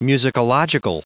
Prononciation du mot musicological en anglais (fichier audio)
Prononciation du mot : musicological